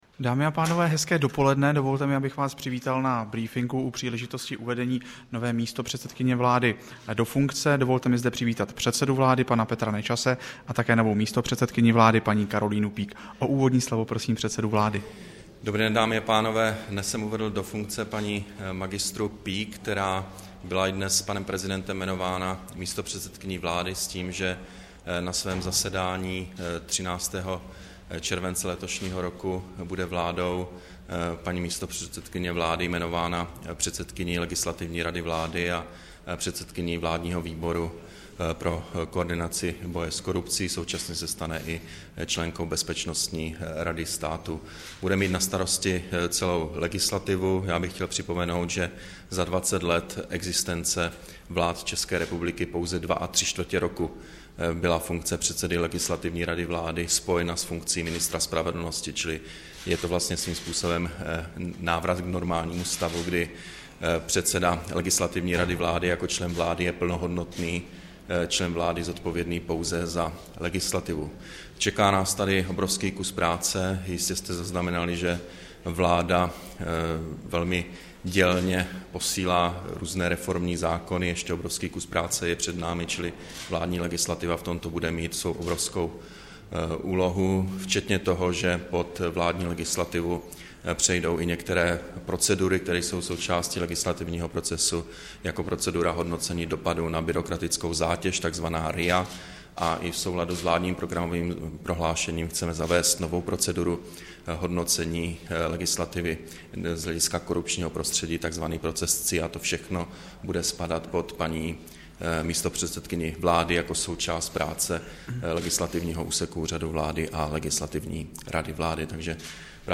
Tiskový brífink u příležitosti uvedení místopřesedkyně Karolíny Peake do funkce, 1. července 2011